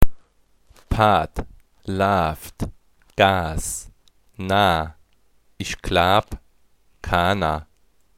Elementare Tonbeispiele zur saarländischen Sprache
Das saarländische ae hat sich zu einem eigenständigen Laut entwickelt.
Die Verschmelzung der beiden lautschrittypischen Zeichen führt zum saarländischen ae (vor allem im Ostertal anzutreffen).